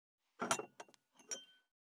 238,テーブル等に物を置く,食器,グラス,コップ,工具,小物,雑貨,コトン,トン,ゴト,ポン,ガシャン,ドスン,ストン,カチ,タン,バタン,スッ,サッ,コン,ペタ,パタ,チョン,コス,カラン,ドン,チャリン,
コップ効果音厨房/台所/レストラン/kitchen物を置く食器